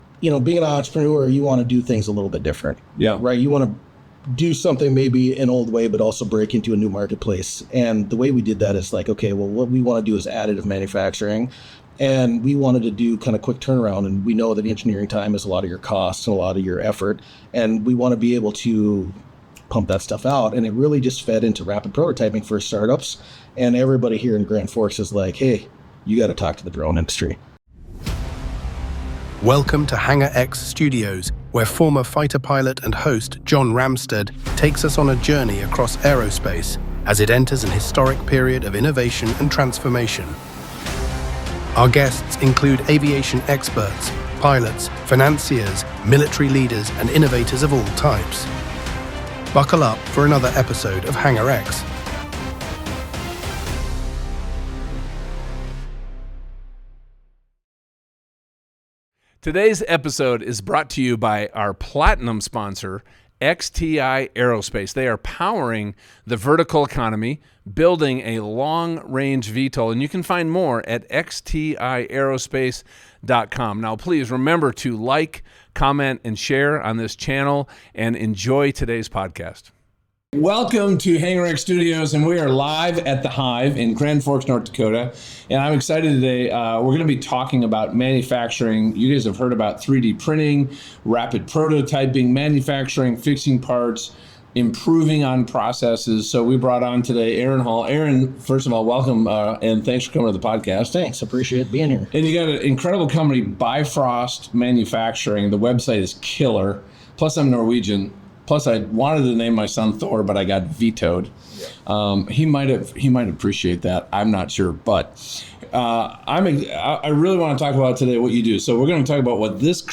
Broadcasting from The Hive in Grand Forks, North Dakota, the conversation dives into the startup's origins, their game-changing use of SAF and SLS 3D printing, and how their work is transforming timelines and costs across the drone, aerospace, agriculture, and defense industries.